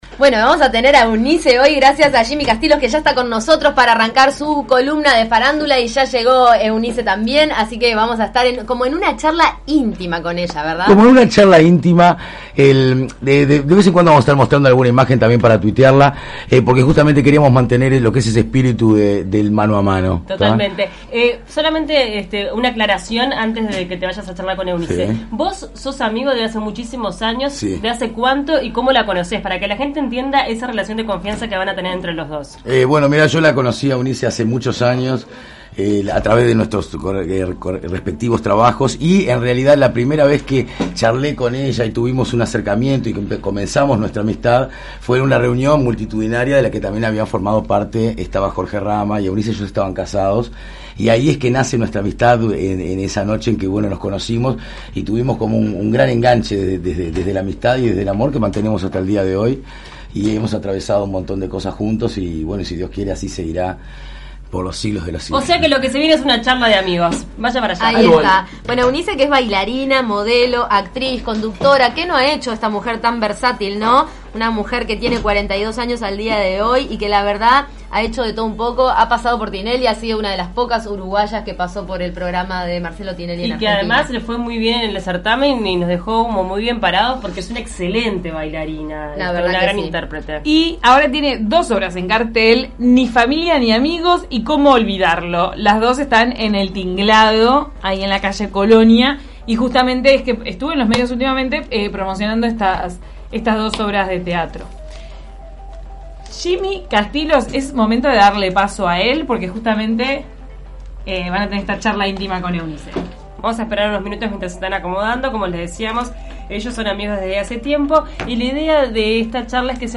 entrevista íntima